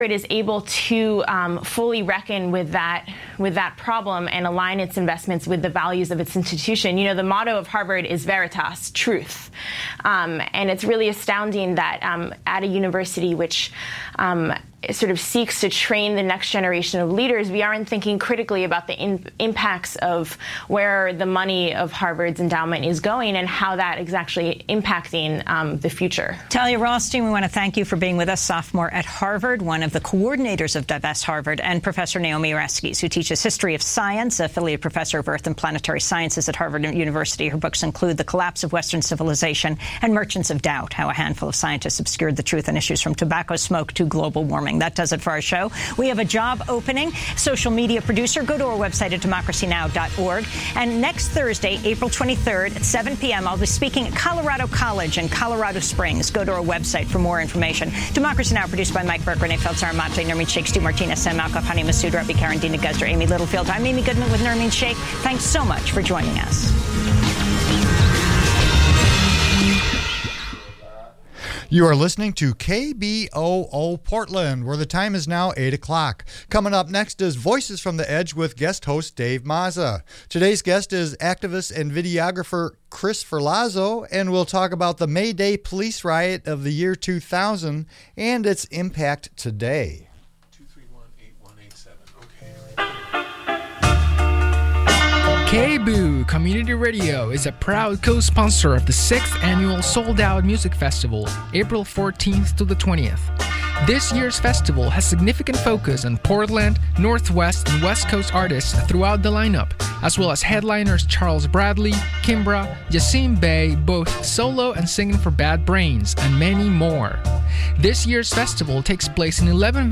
Progressive talk radio from a grassroots perspective
With an hour to invest, the call-in format engages listeners in meaningful conversations about crucial issues like racial disparity, government accountability, environmental justice and politics on local, state and national levels.